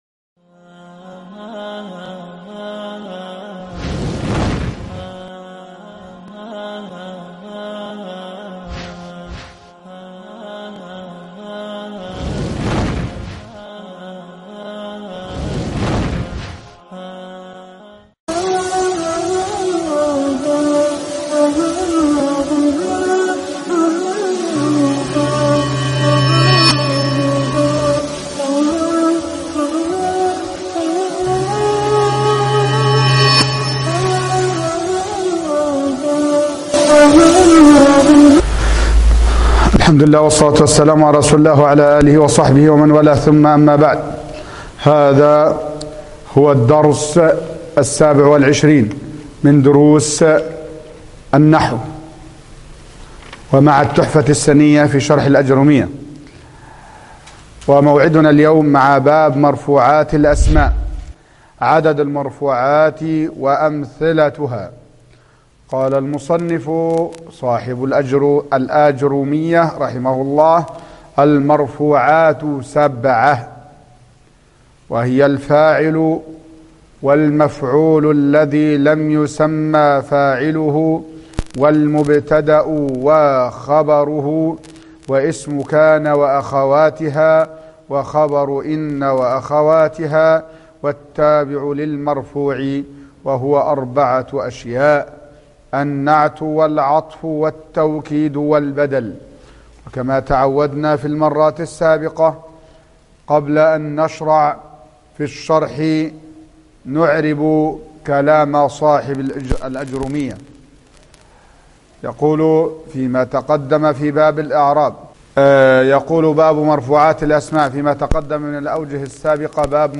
الدرس 27 باب المرفوعات ( التحفة السنية شرح كتاب الآجرومية )